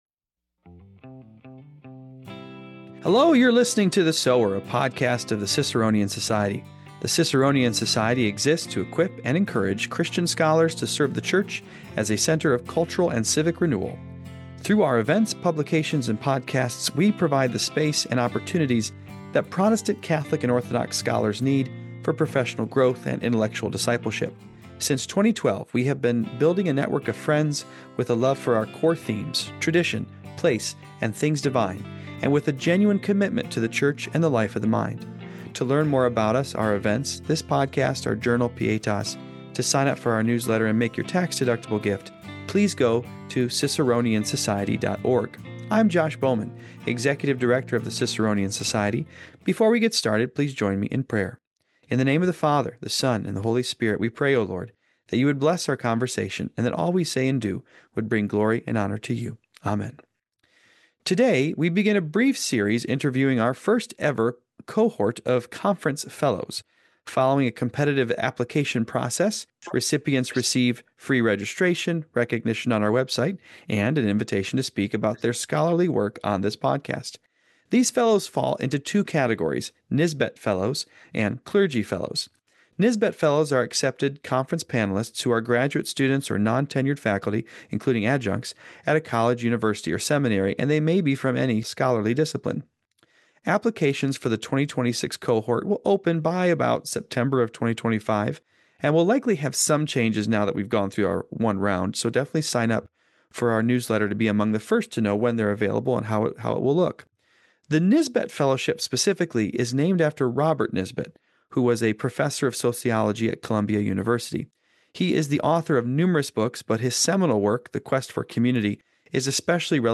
It's also painfully obvious that you're listening to two Midwesterners.